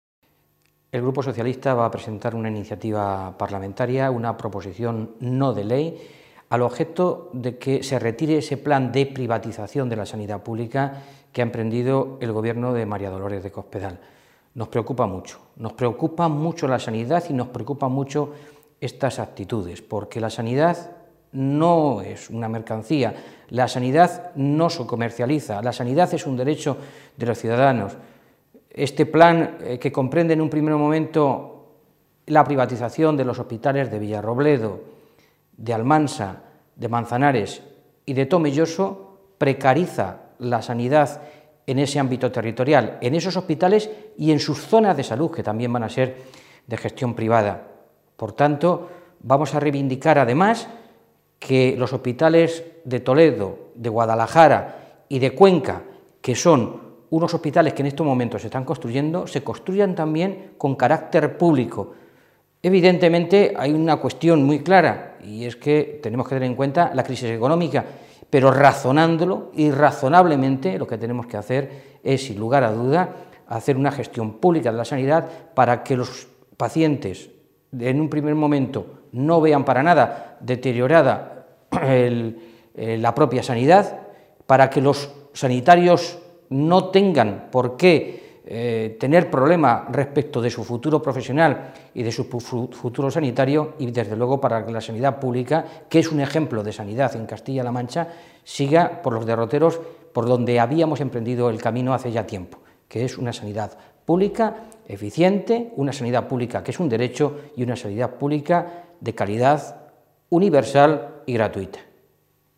Fernando Mora, portavoz de Sanidad del Grupo Socialista
Cortes de audio de la rueda de prensa